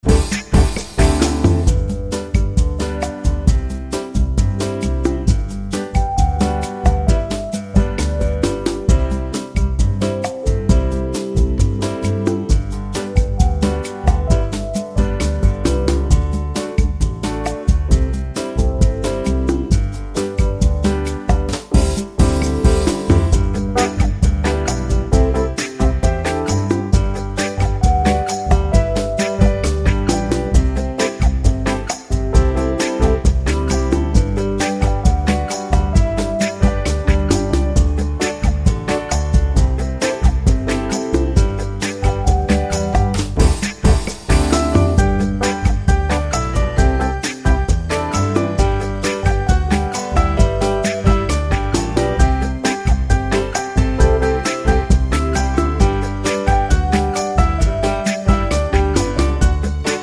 Up 3 Semitones for Female